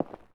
Concret Footstep 05.wav